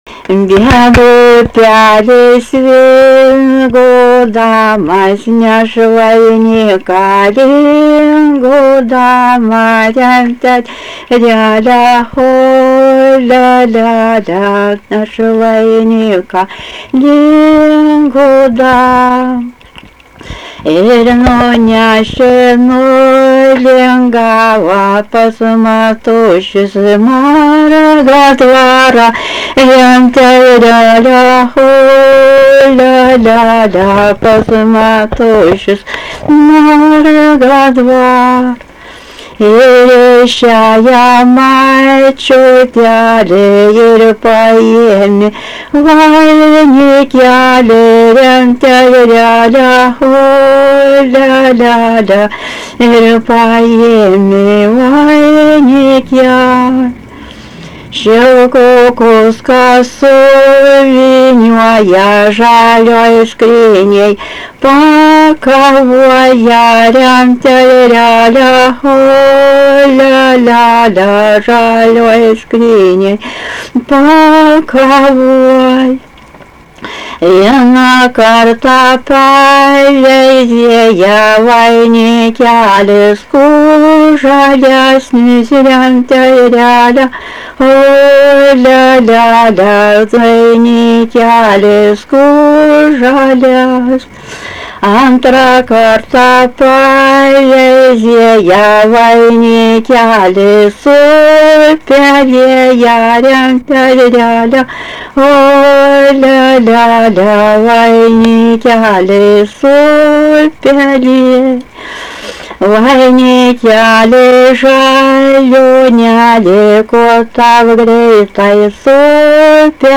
daina
Paškuvėnai
vokalinis